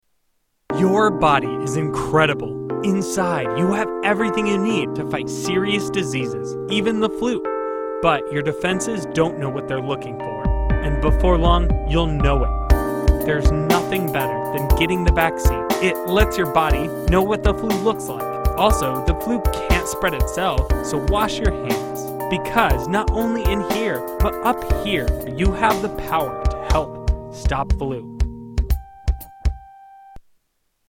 Tags: Media Flu PSA's Flu Public Service Announcements H1N1